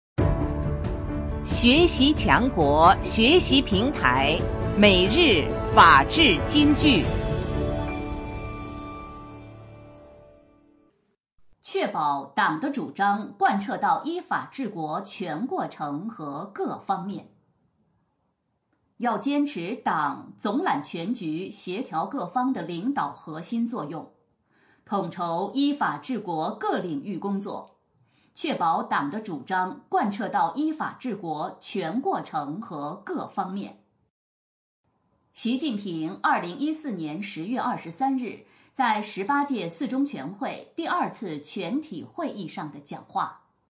每日法治金句（朗读版）|确保党的主张贯彻到依法治国 全过程和各方面 _ 学习宣传 _ 福建省民政厅